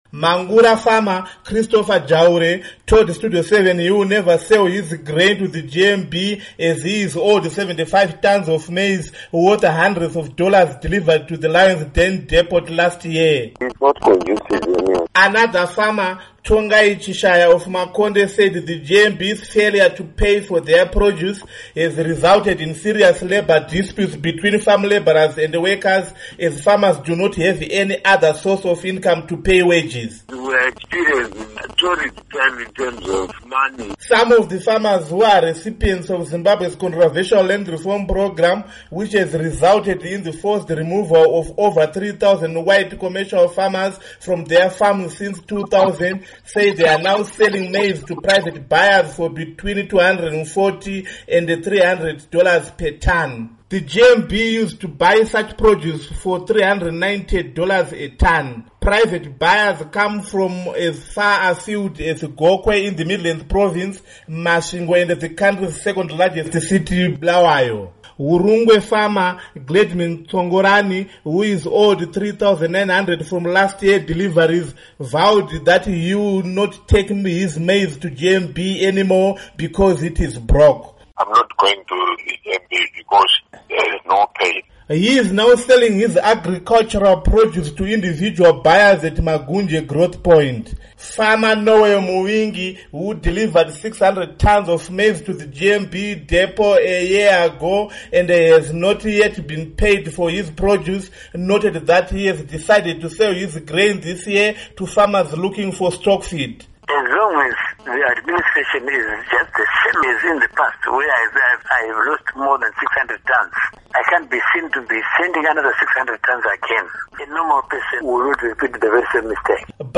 Report on GMB Problems